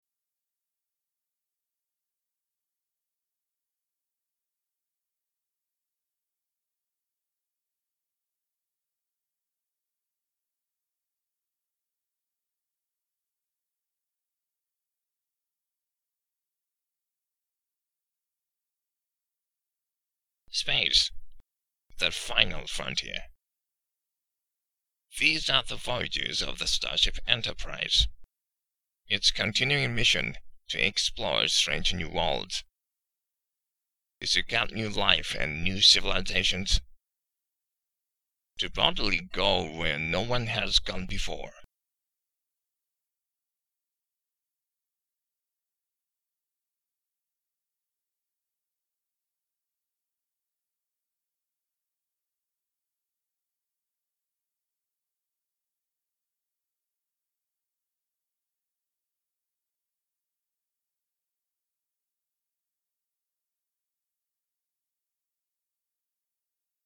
Ｚが破裂音？
/ou/の/u/を発音していないというのは、そうです、私の癖です。
私の耳には破裂音に聞こえませんけどね。
ＢＧＭ無しの私の音声部分だけのファイルをうｐします。
先日の音声では、オリジナルが英国英語なので、その英国風イントネーションメソッドを使っているためにいつもより子音が鋭いです。